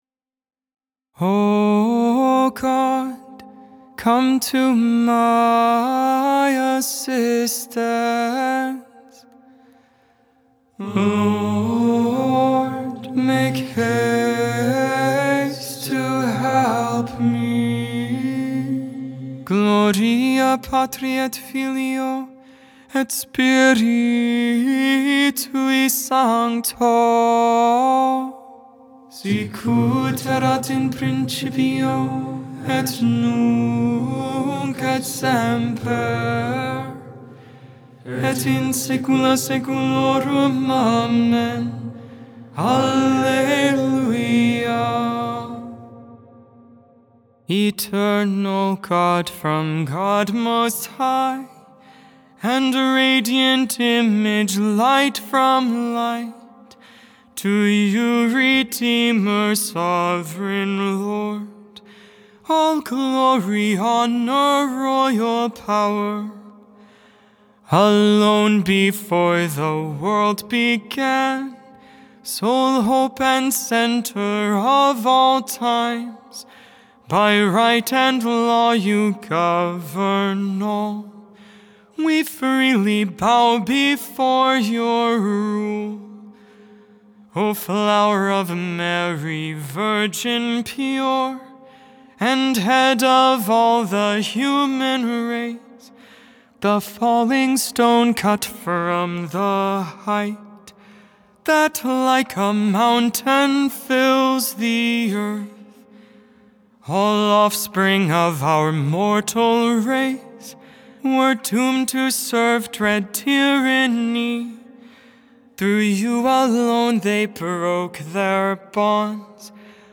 (Gregorian tone 5)